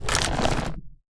creaking_deactivate.ogg